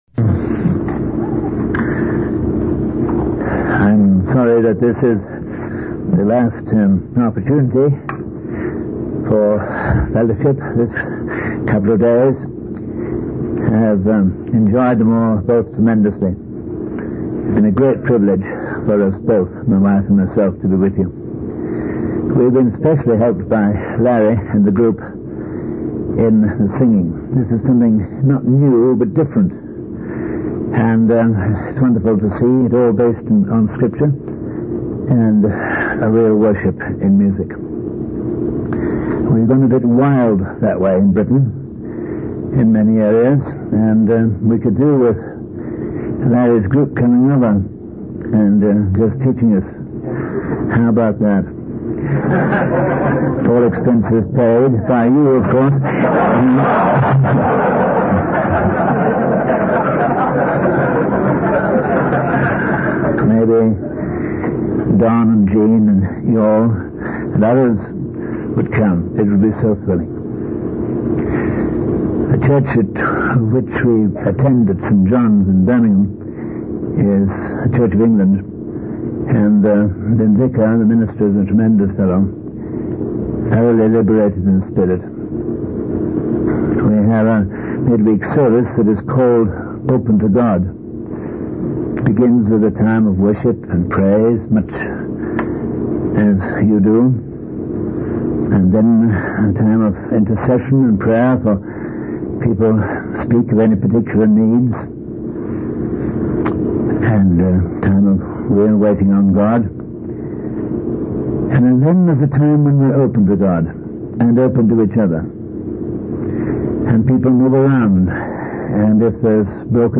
In this sermon, the preacher discusses the importance of the miracle of a Spirit-filled life. He refers to the crowd's questions on the day of Pentecost, asking what the events meant and what they should do.